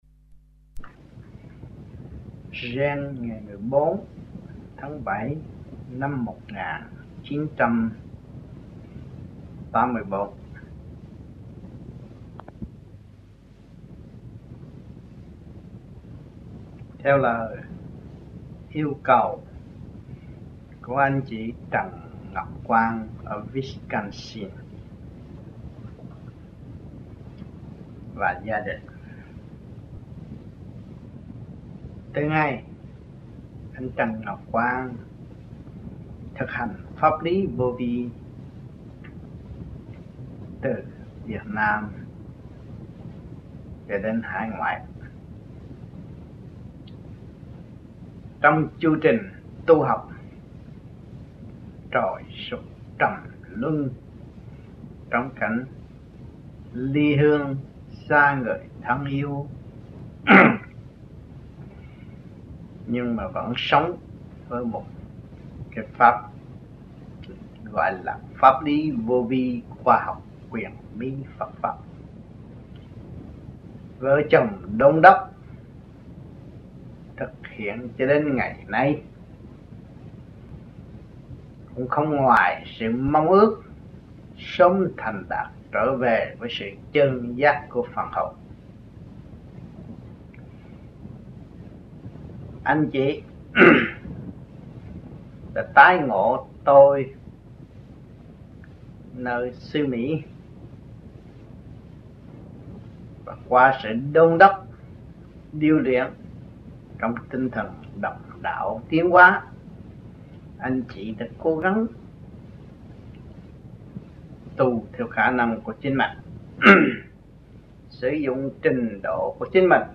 1984-07-14 - GIEN - THUYẾT PHÁP